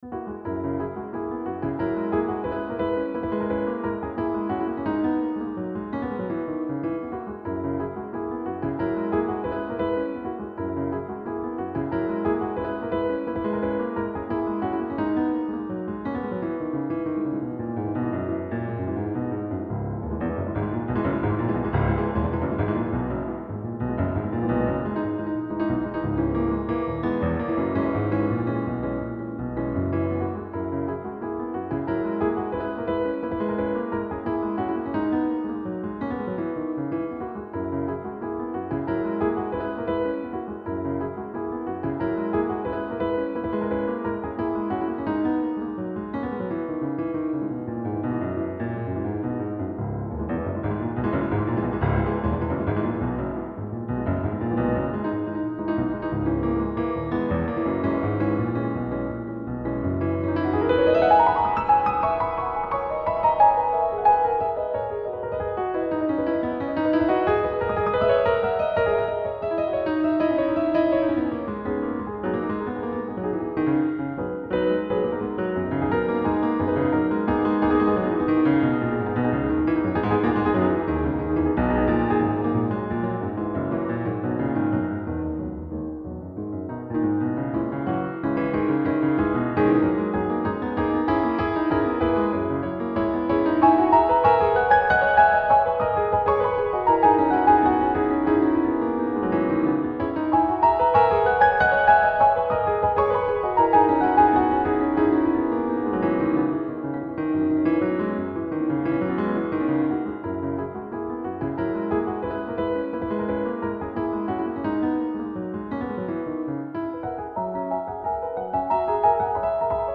J’utilise un piano numérique.
Vous pouvez appliquer un certain nombre de filtres et en particulier assigner un peu de réverbération qui donne une amplitude « pro » au son.